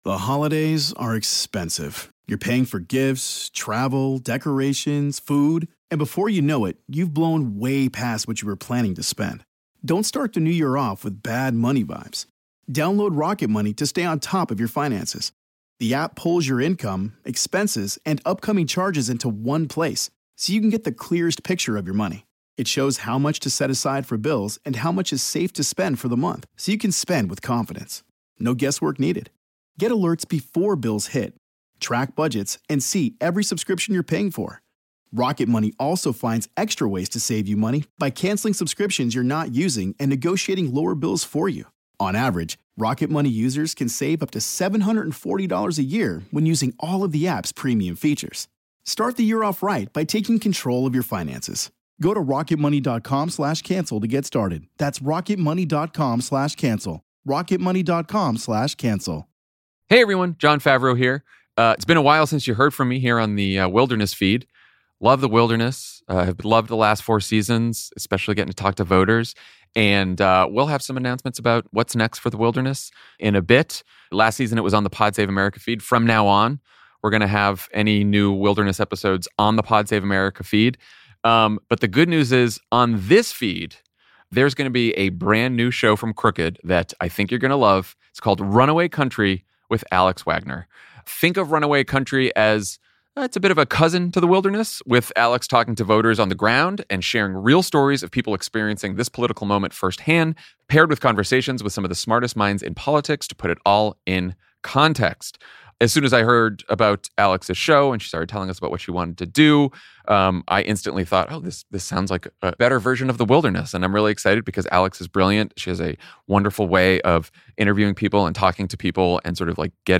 Hey everyone, Jon Favreau here with a special announcement. From now on, new episodes of The Wilderness will appear on the Pod Save America feed, and we’ll have some updates soon about when that’s coming!